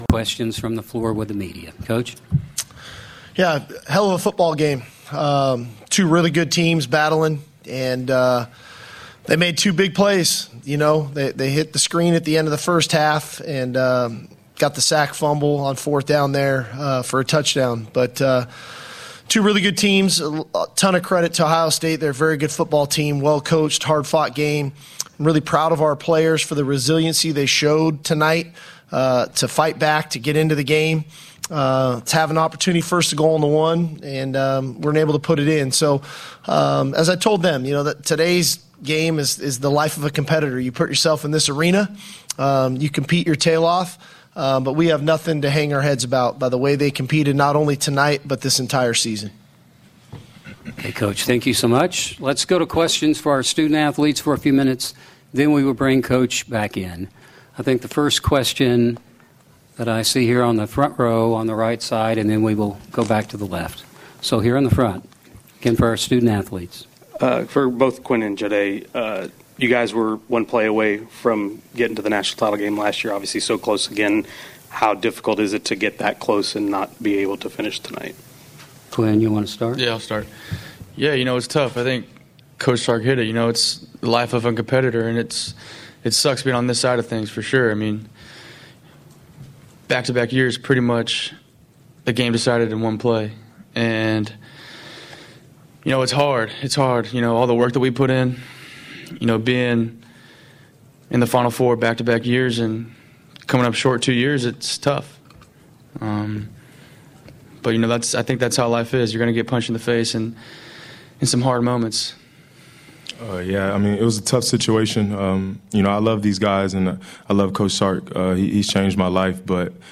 Texas Longhorns Postgame Press Conference after 28-14 CFP Semifinal loss to Ohio State; Steve Sarkisian, Quinn Ewers, Jahdae Barron